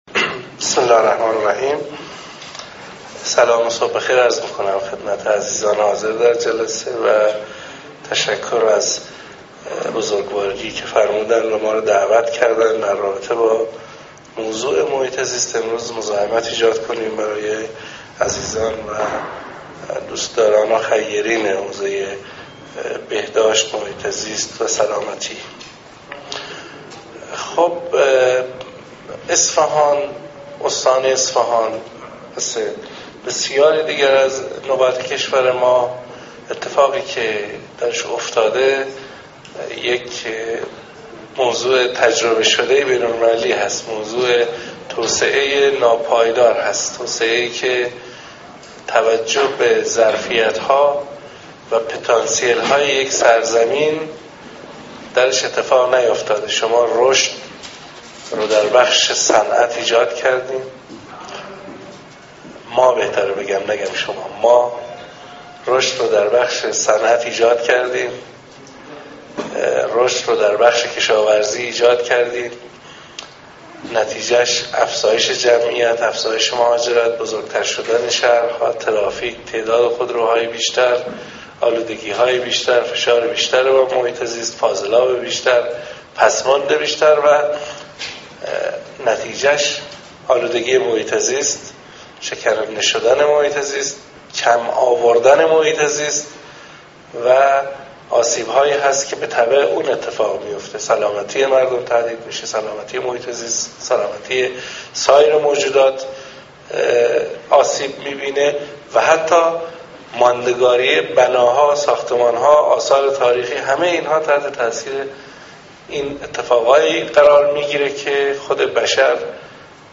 سخنرانی جلسه عمومی مجمع خیرین بهداشت و سلامت استان اصفهان (امام هادی (ع)) در تاریخ 95/8/15 جناب آقای مهندس ظهرابی مدیرکل محترم سازمان حفاظت محیط زیست استان در خصوص " نقش سازمان محیط زیست در ارتقاء بهداشت و سلامت" برگزار گردید که از طریق لینک زیر قابل دانلود می باشد.